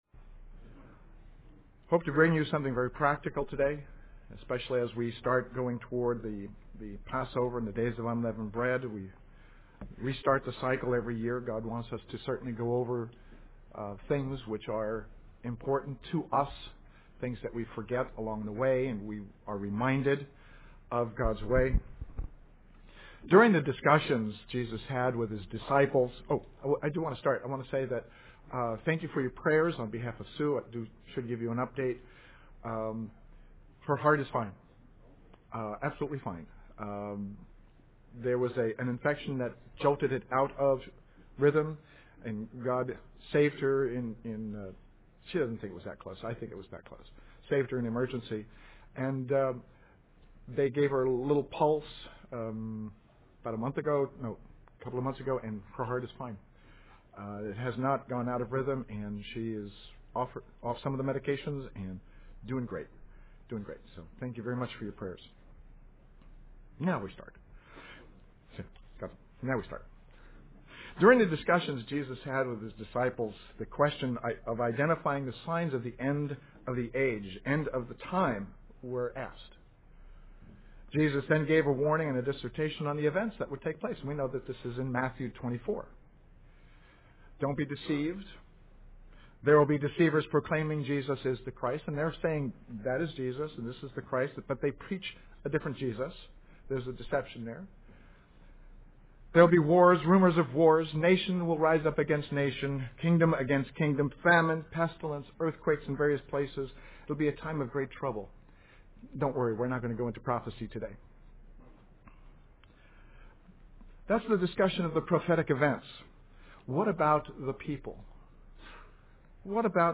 Given in San Francisco Bay Area, CA
Here is one practical answer many would not consider UCG Sermon Studying the bible?